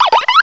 cry_not_tirtouga.aif